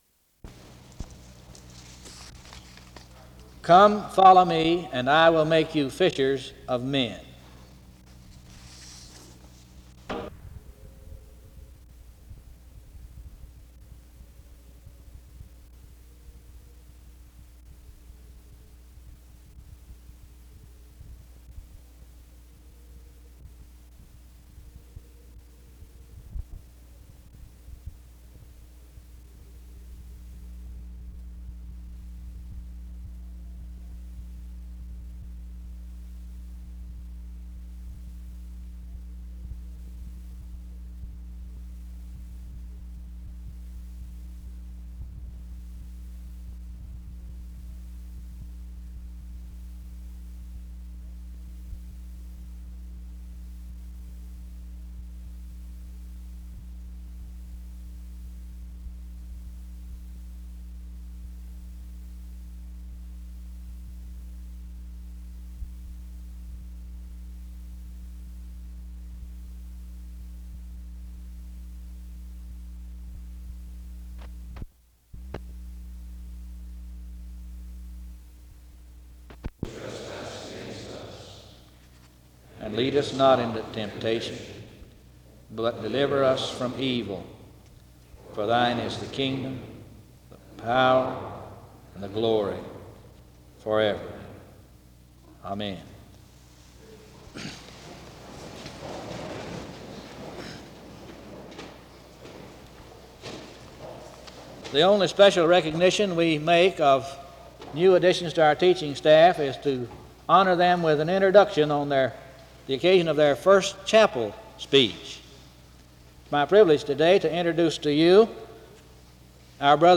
The Lord’s prayer takes place from 1:15-1:36. An introduction to the speaker is given from 1:44-2:21.
SEBTS Chapel and Special Event Recordings SEBTS Chapel and Special Event Recordings